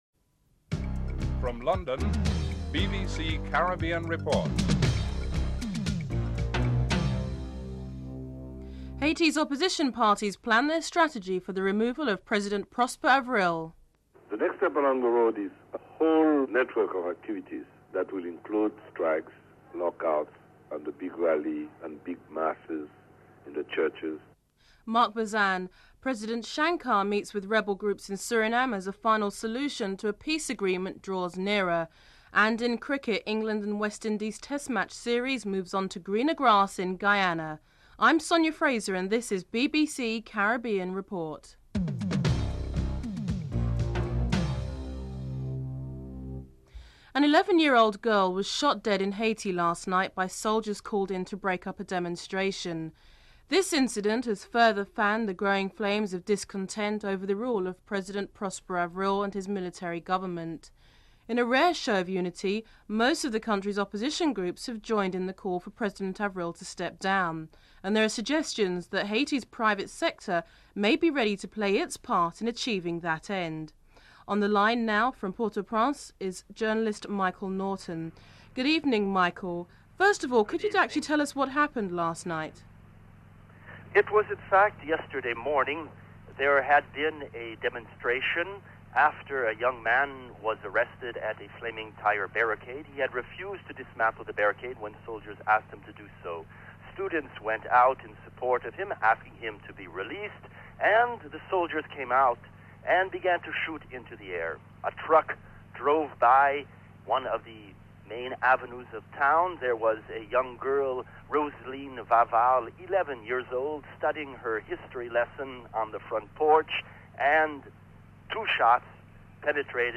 1. Headlines (00:00-00:46)
Telephone interview